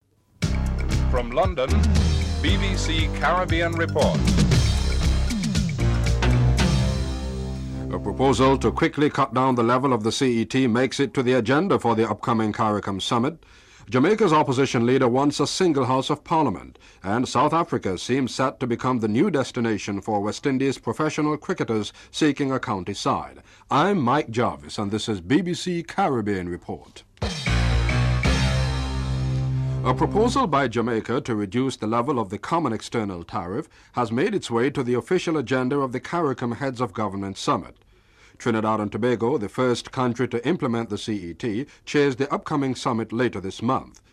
The British Broadcasting Corporation
1. Headlines (00:00-00:30)